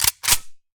select-smg-1.ogg